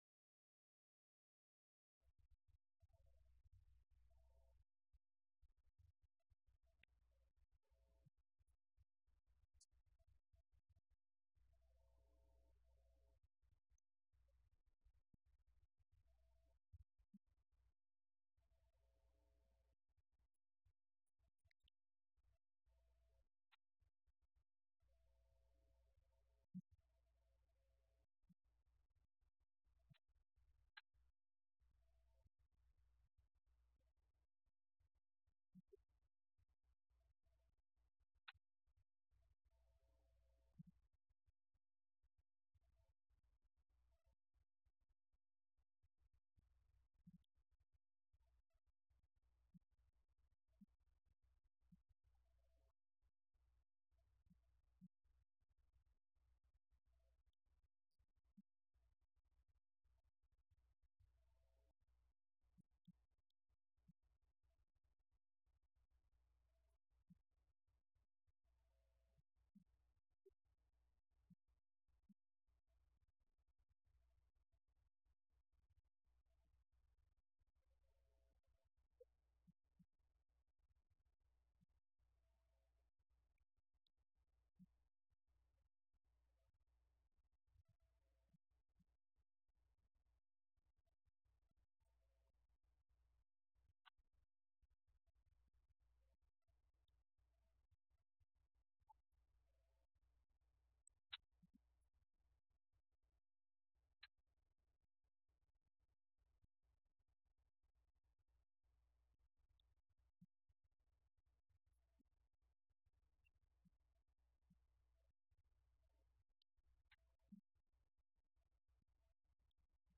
Event: 17th Annual Schertz Lectures Theme/Title: Studies in Job
lecture